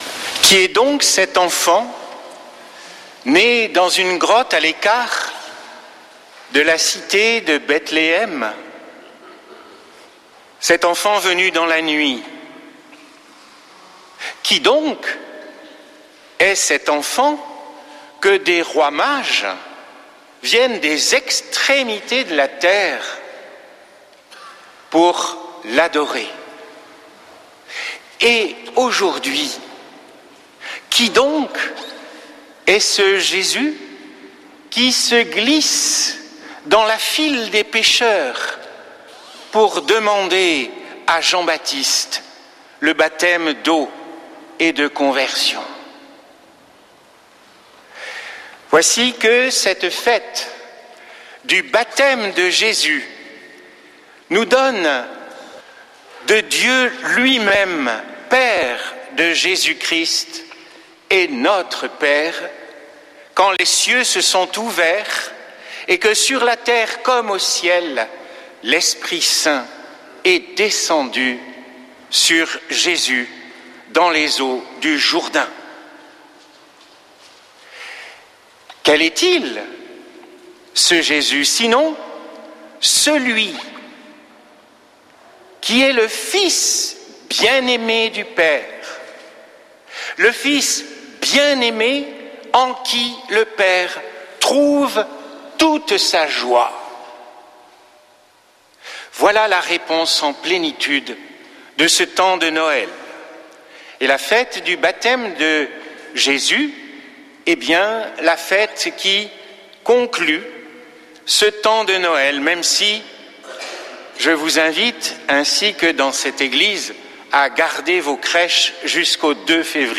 Messe depuis le couvent des Dominicains de Toulouse
homelie